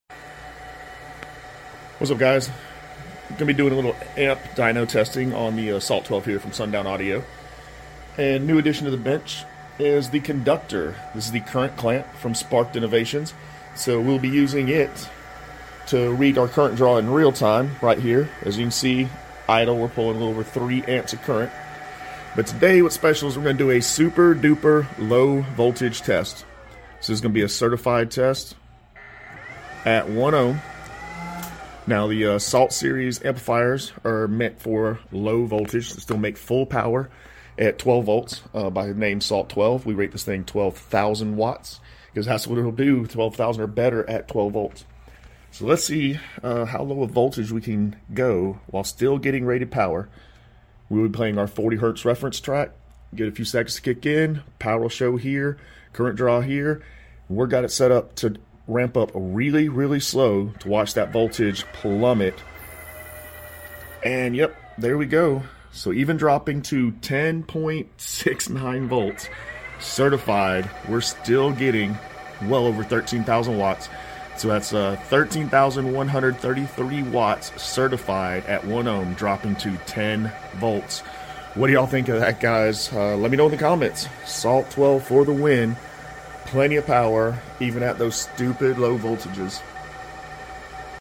car audio amplifier subwoofer Bass sound videos